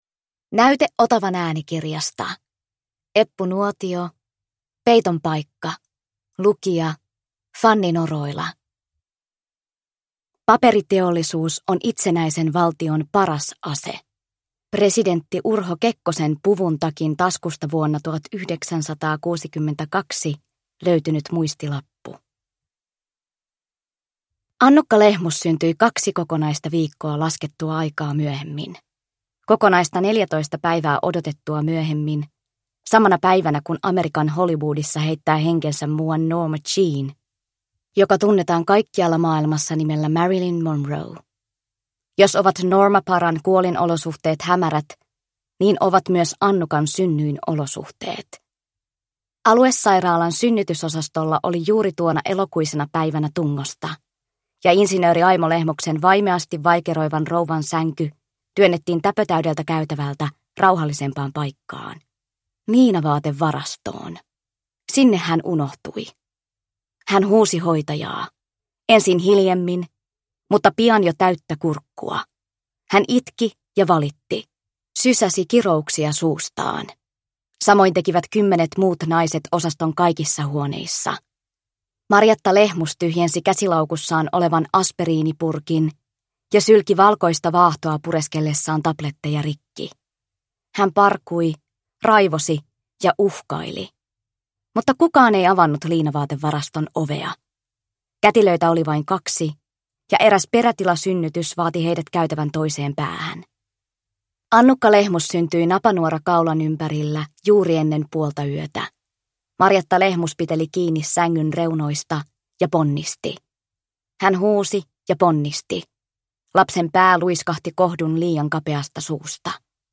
Peiton paikka – Ljudbok – Laddas ner